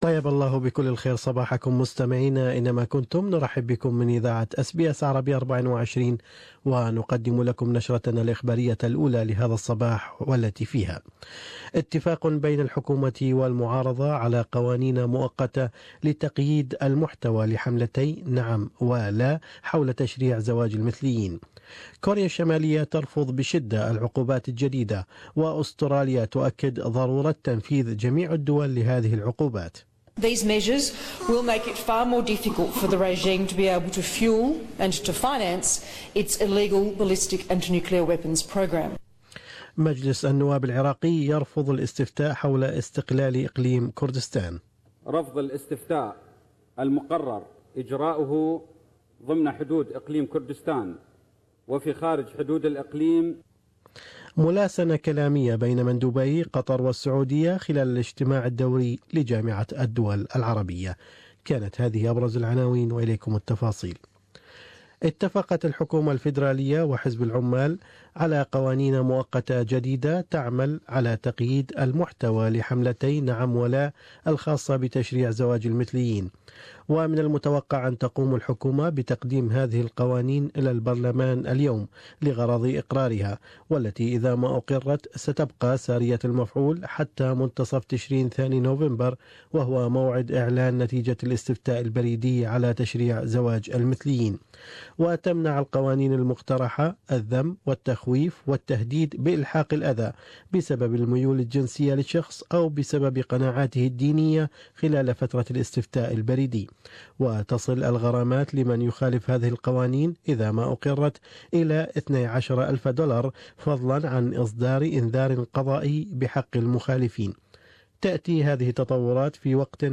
News Bulletin: Same-sex marriage campaigners could face fines if material includes hate speech